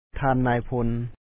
Pronunciation Notes 20
thāan náay phón General